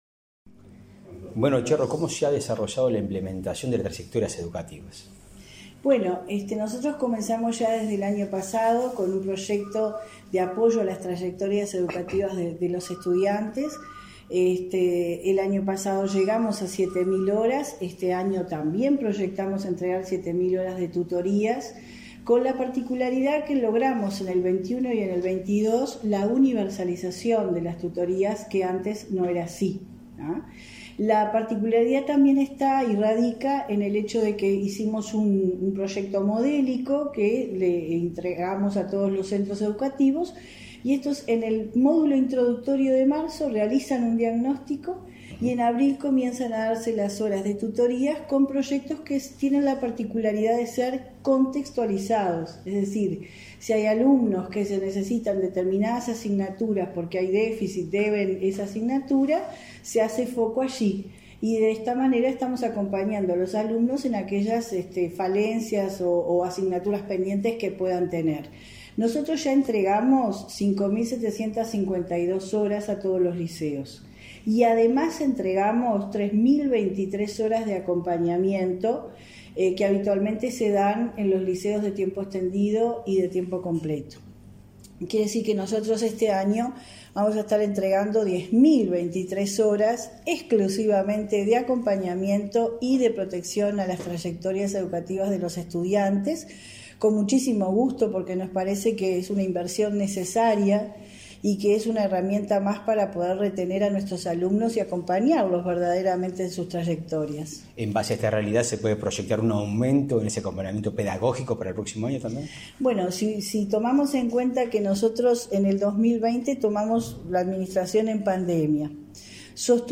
Entrevista a la directora general de Educación Secundaria de la ANEP, Jenifer Cherro